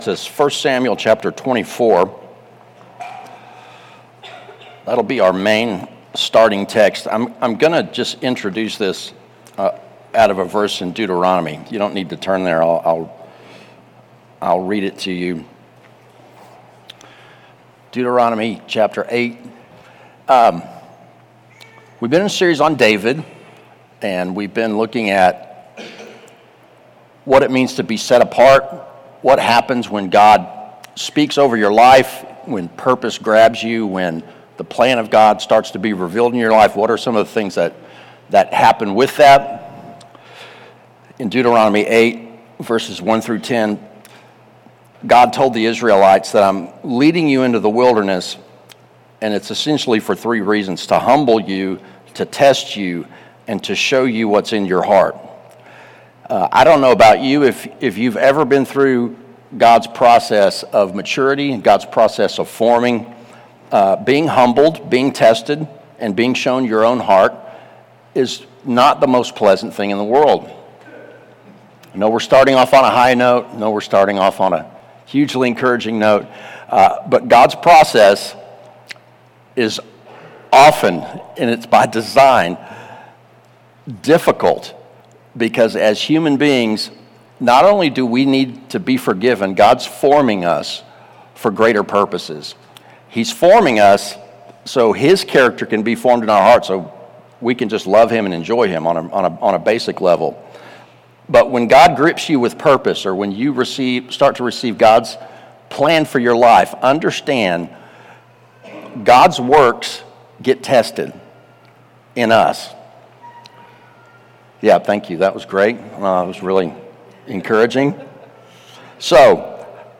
More sermons